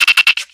Audio / SE / Cries / SEEDOT.ogg